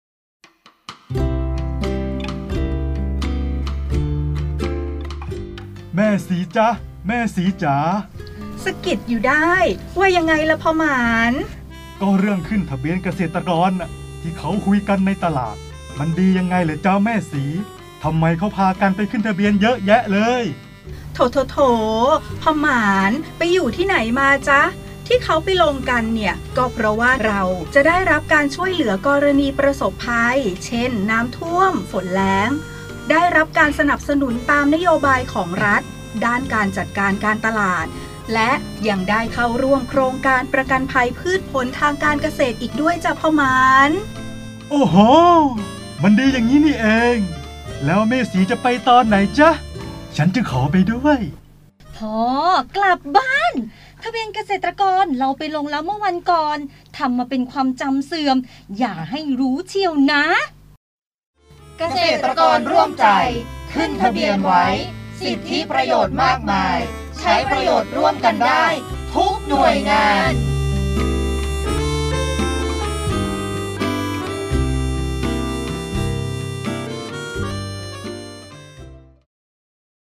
สปอตไขข้อข้องใจทำไมต้องขึ้นทะเบียนเกษตรกร ปี67